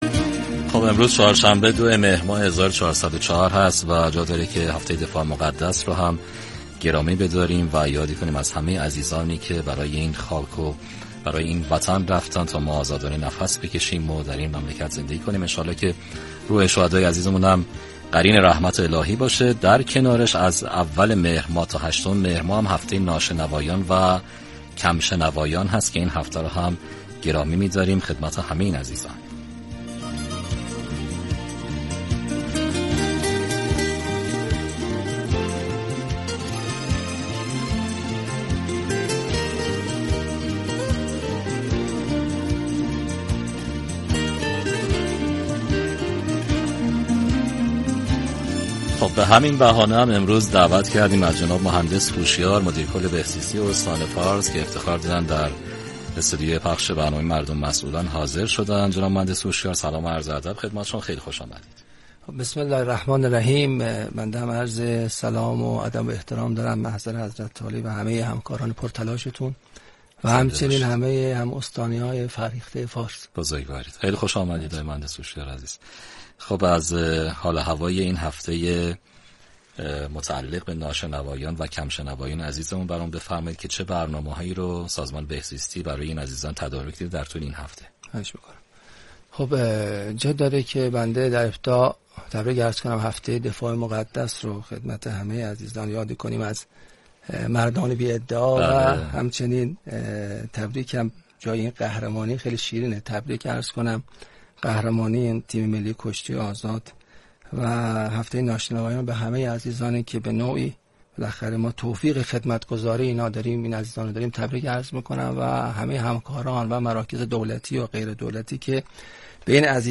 بشنوید / حضور مدیرکل بهزیستی فارس در برنامه ی رادیویی مردم و مسئولان به مناسبت گرامیداشت هفته ی ناشنوایان